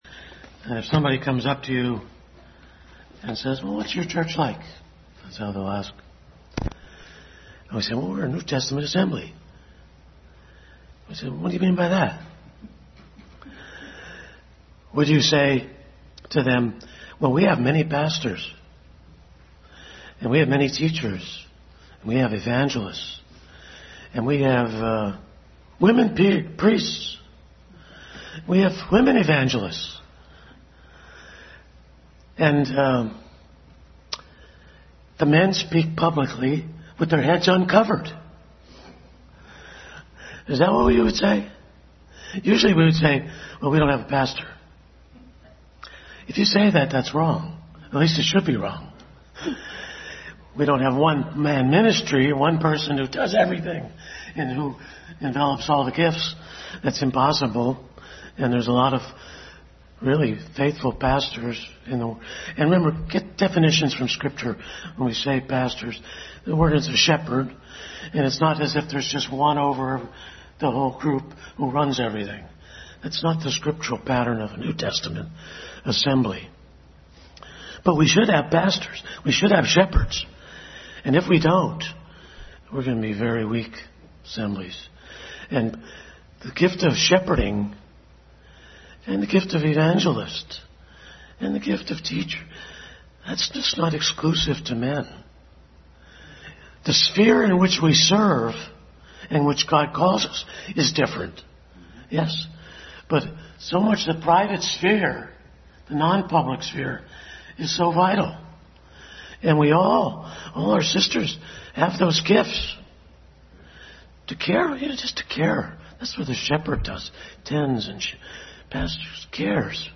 Acts 2:42 Passage: Acts 2:42, 1 Corinthians 11, Service Type: Family Bible Hour Family Bible Hour message.